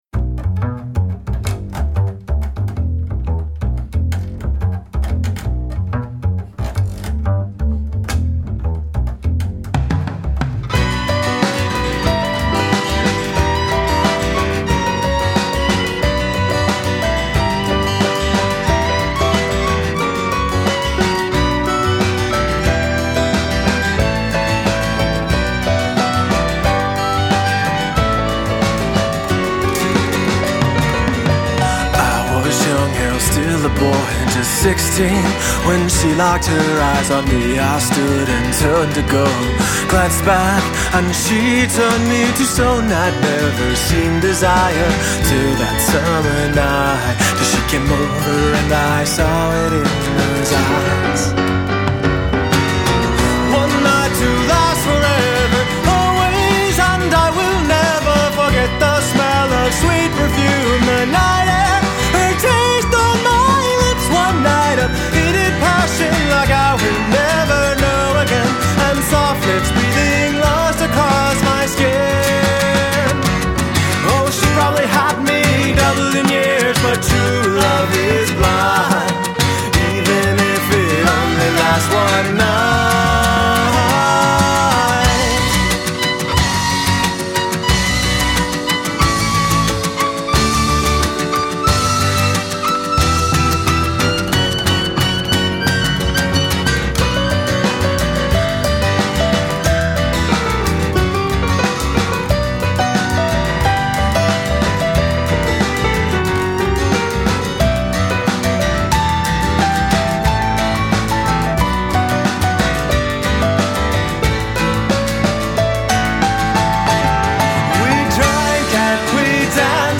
Guitar, Violin, Vocals
Drums
Piano
Upright Bass